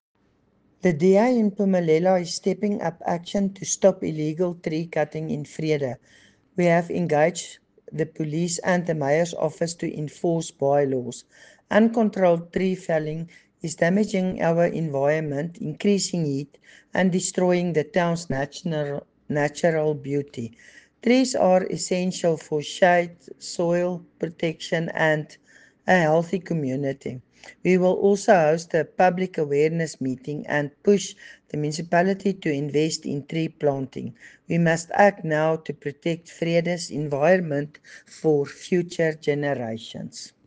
Afrikaans soundbites by Cllr Doreen Wessels and